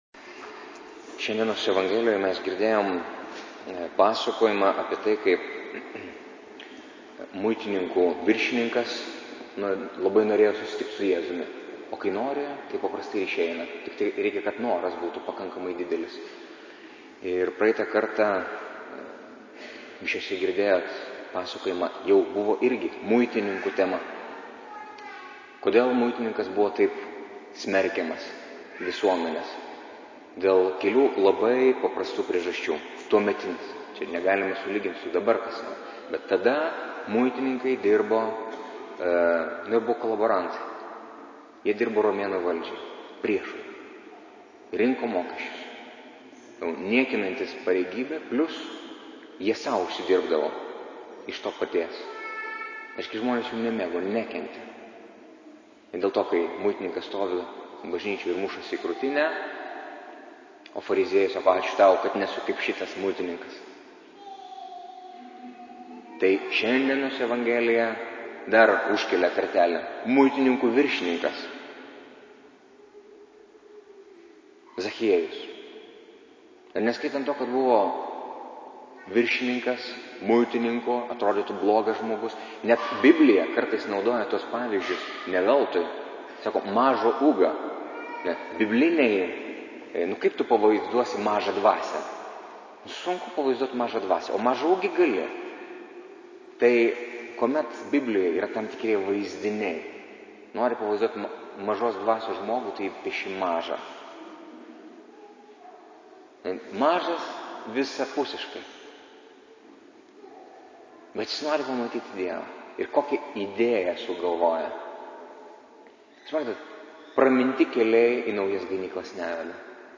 Audio pamokslas Nr1: 2016-10-30-xxxi-eilinis-sekmadienis